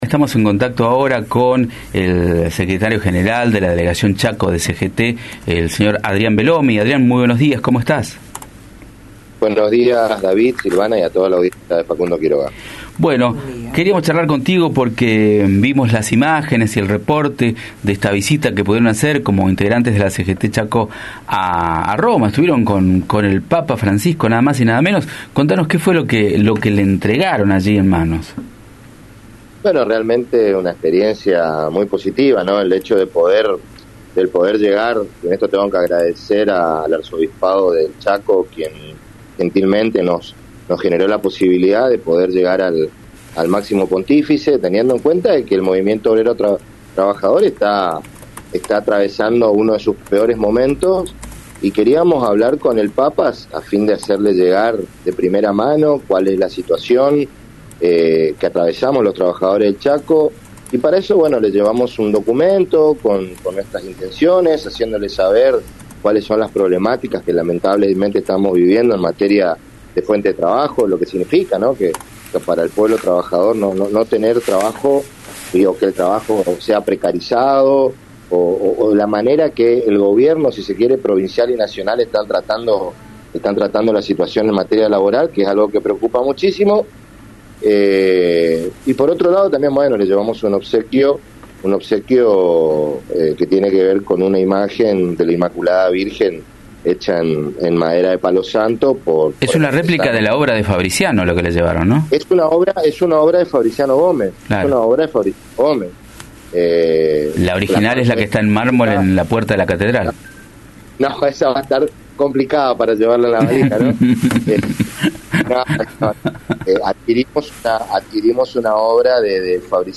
En diálogo con Radio Facundo Quiroga